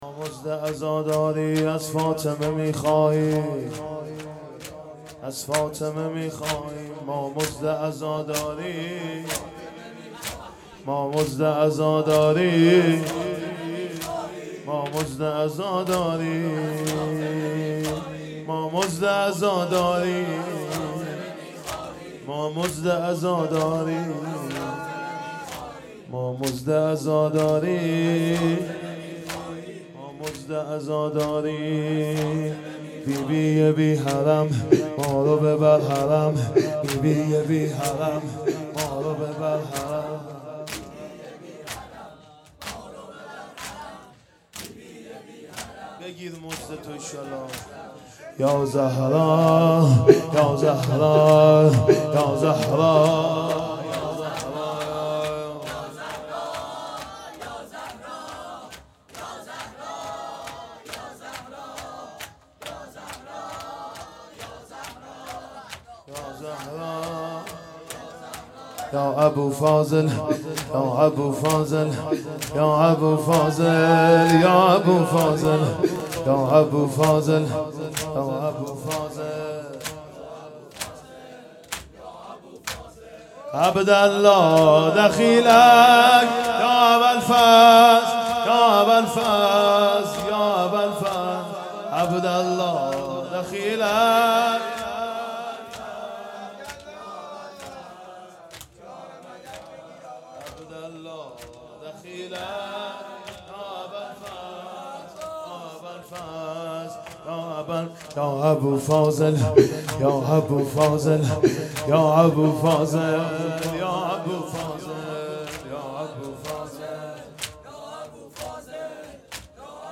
شور
محرم 1440 _ شب یازدهم